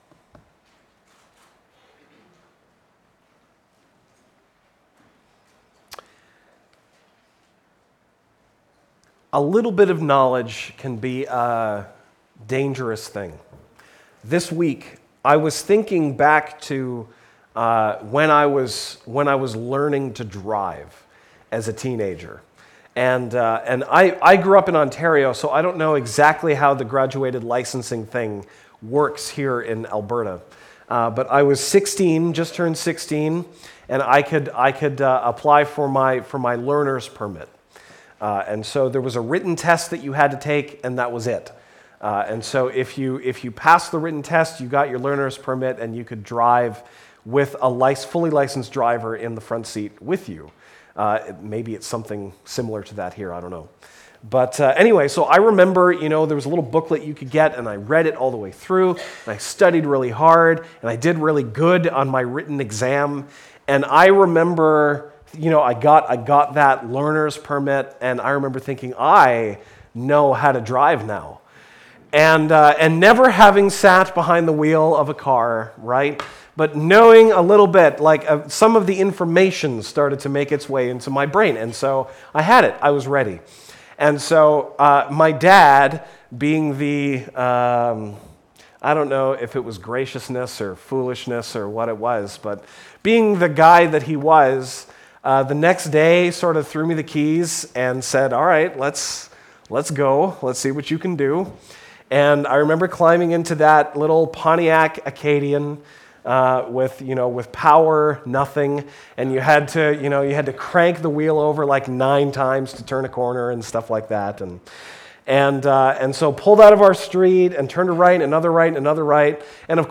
Bible Text: Mark 8:31-38 | Preacher